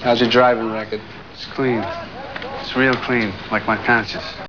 Martin Scorsese talking about 'Taxi Driver'  (0m4s, 49Kb., .wav)